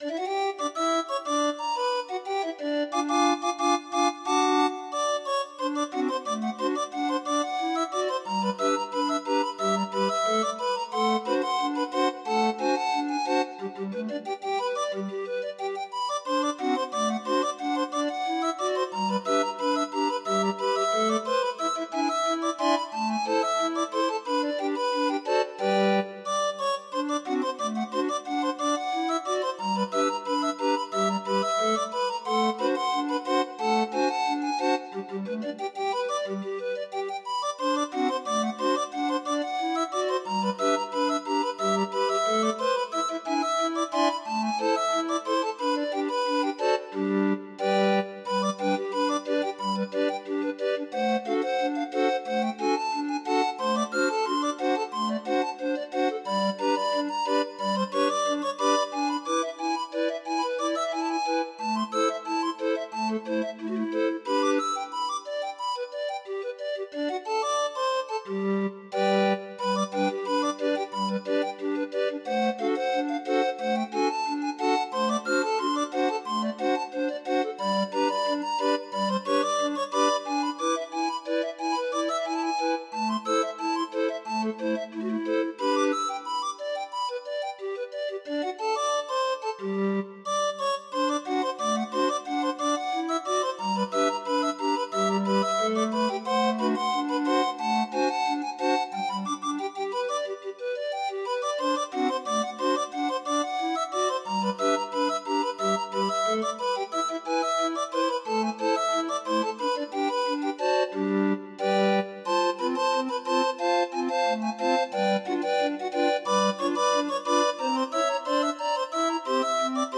Demo of 26 note MIDI file